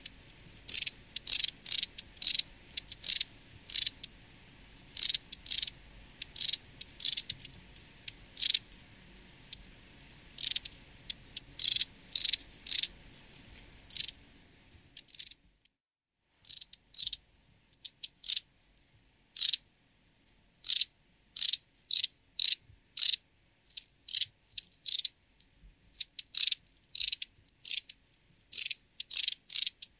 Photos de Mésange à longue queue - Mes Zoazos
mesange-longue-queue.mp3